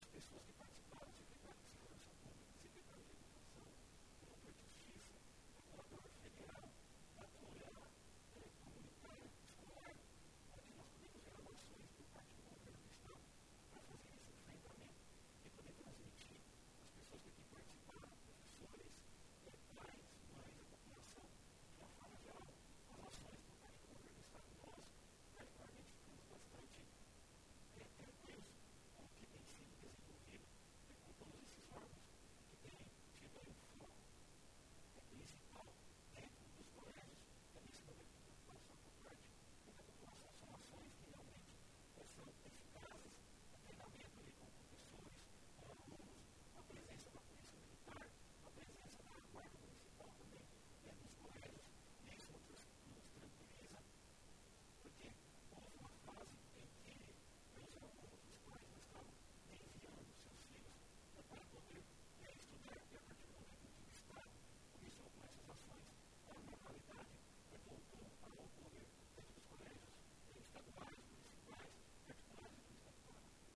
Sonora deputado Soldado Adriano José -PP